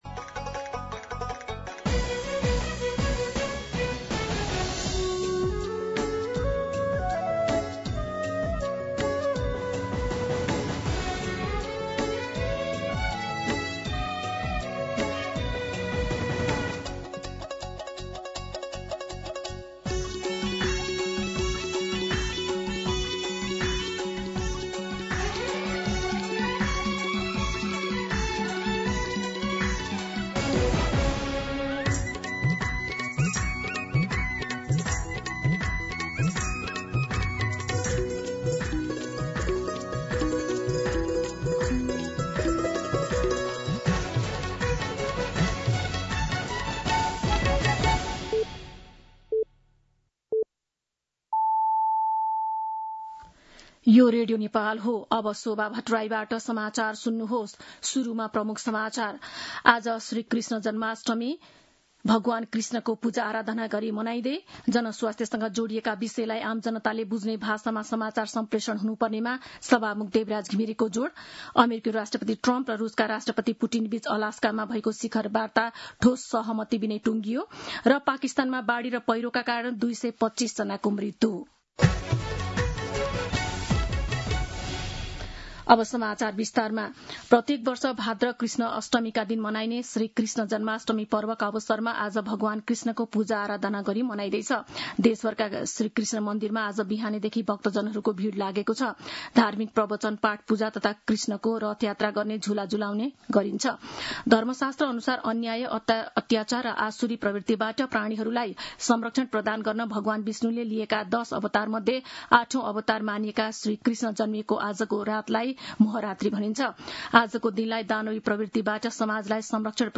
दिउँसो ३ बजेको नेपाली समाचार : ३१ साउन , २०८२